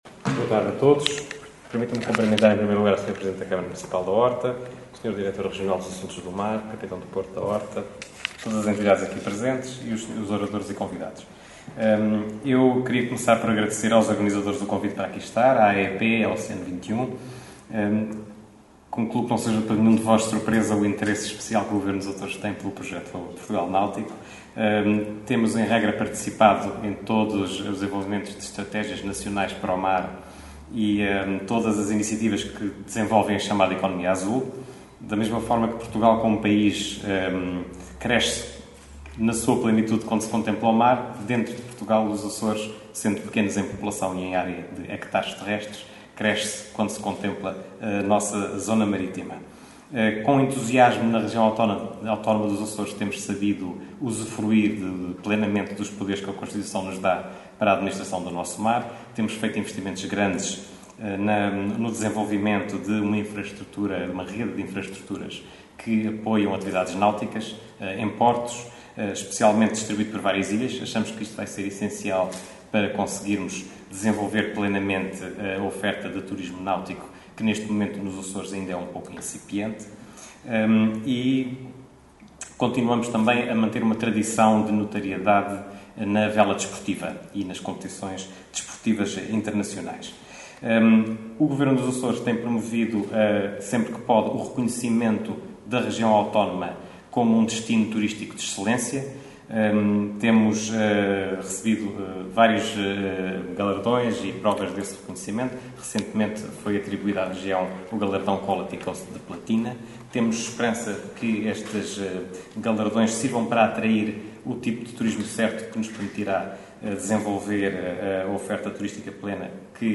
Fausto Brito e Abreu falava no Salão Nobre da Câmara Municipal da Horta, na sessão de apresentação do projeto ‘Portugal Náutico’ nos Açores.